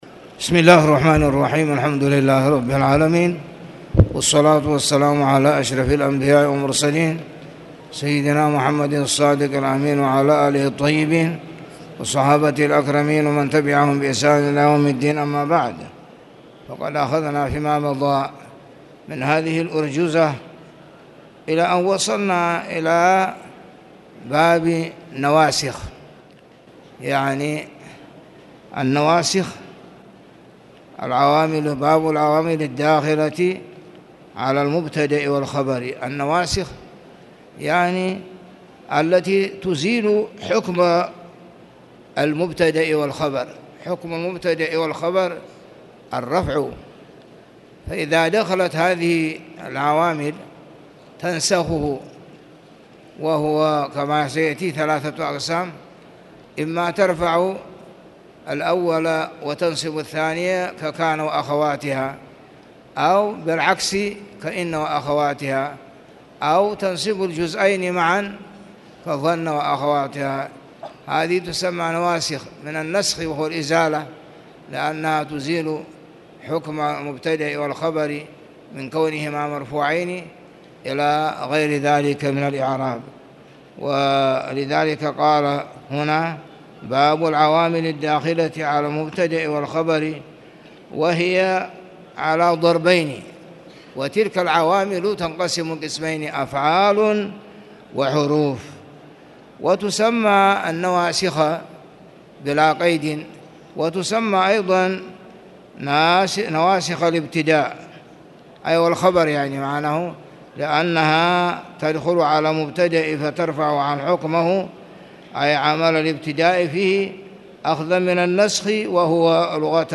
تاريخ النشر ١٨ محرم ١٤٣٨ هـ المكان: المسجد الحرام الشيخ